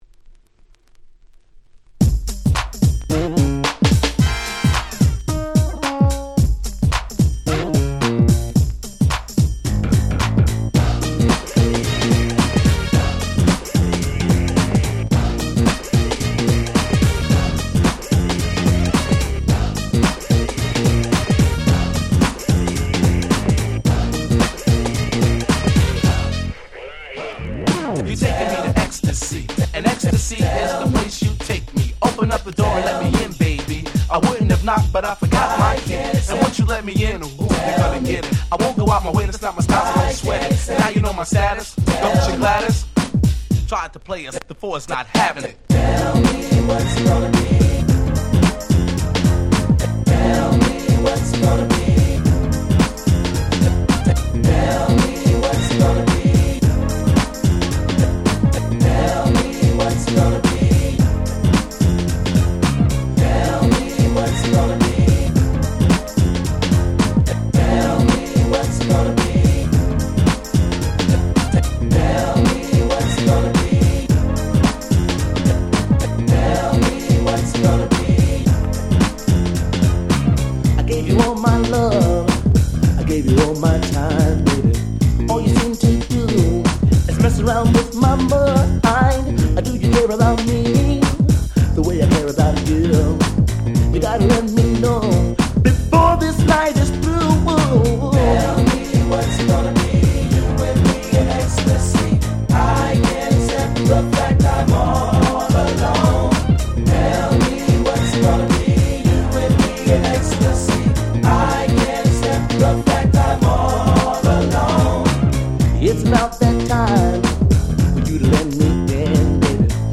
91' Nice New Jack Swing !!
90's ニュージャックスウィング ハネ系